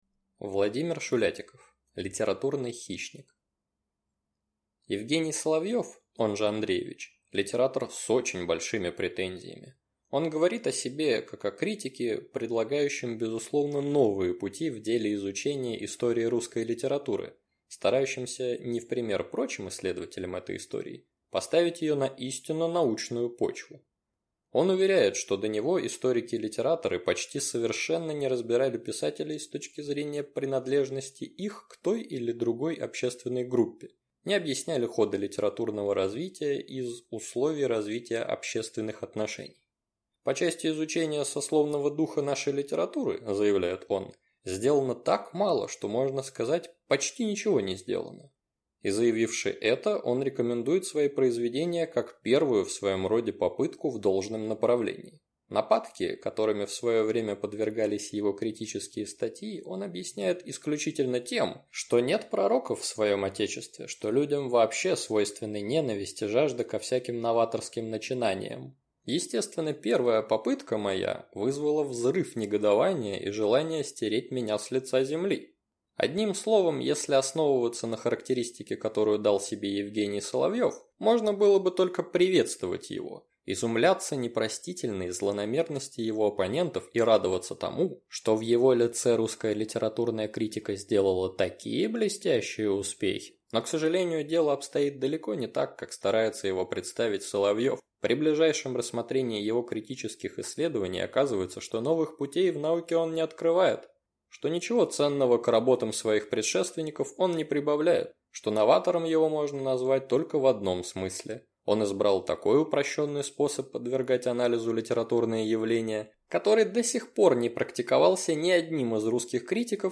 Аудиокнига Литературный хищник | Библиотека аудиокниг